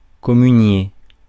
Ääntäminen
Ääntäminen France: IPA: [kɔ.my.nje] Haettu sana löytyi näillä lähdekielillä: ranska Käännöksiä ei löytynyt valitulle kohdekielelle.